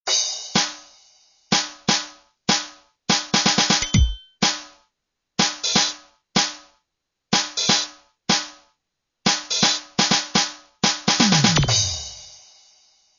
Bicie - doplnky z karty Bicie - doplnky - upravenΘ
Maj· prφli╣ ostr² zvuk Zv²raznil som nφzke stredy a basy ekvalizΘrom.
stopy_aids_doplnkove_bicie_zdroj.mp3